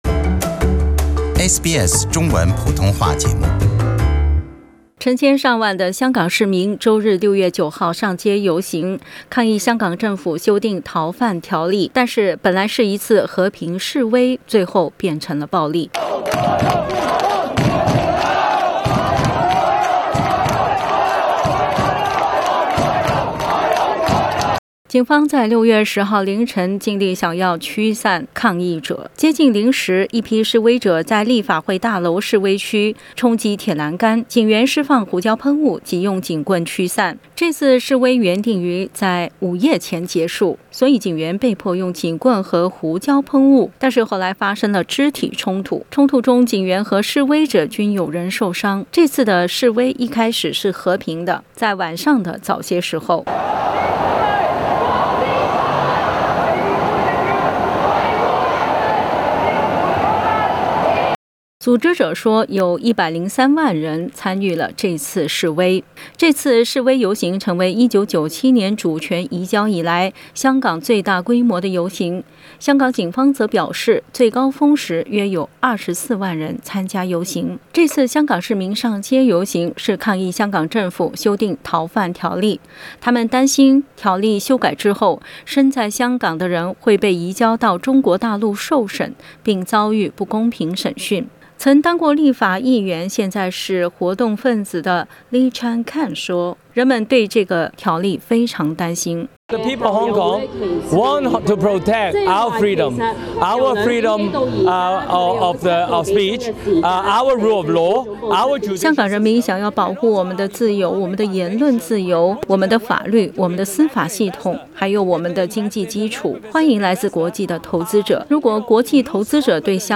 香港逃犯條例有可能在6月底通過。 一位示威者說，她希望林鄭月娥可以聽到市民的反對該條例的呼聲。 另一位已近七旬的示威者說，這個條例沒有經過公眾諮詢，他本人就反對這個條例。